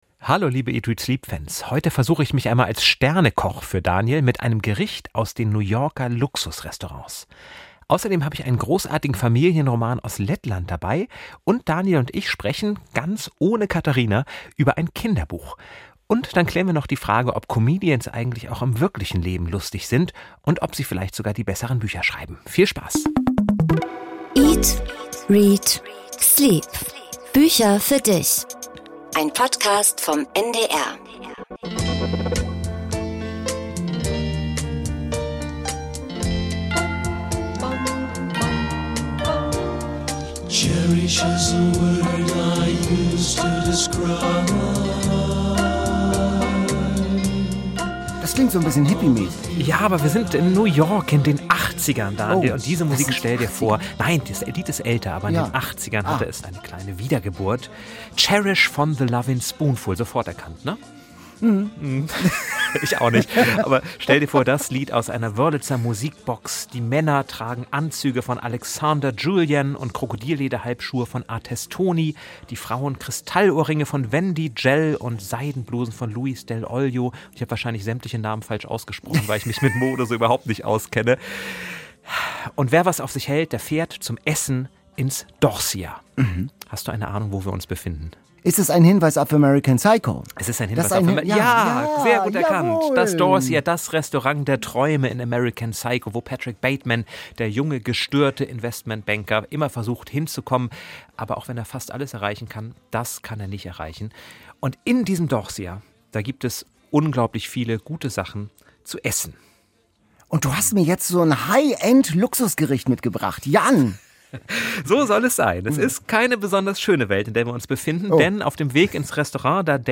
Interview mit Bernhard Hoëcker